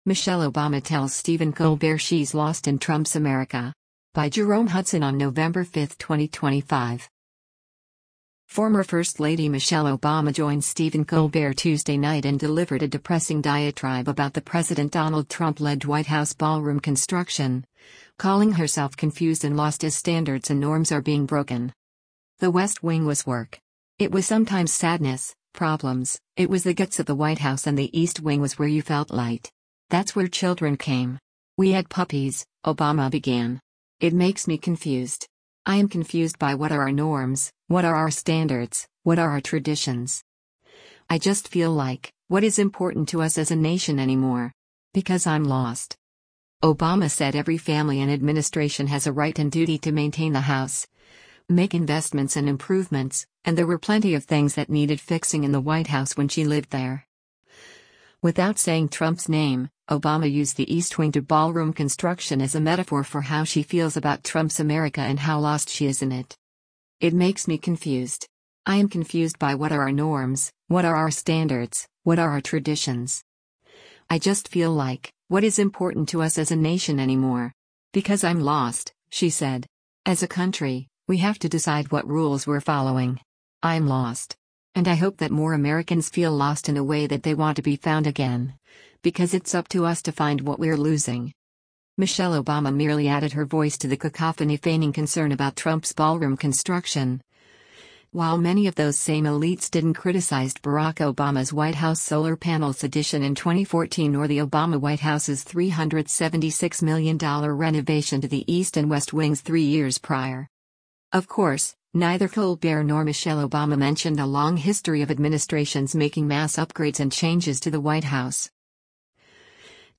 NEW YORK - SEPTEMBER 20: The Late Show With Stephen Colbert.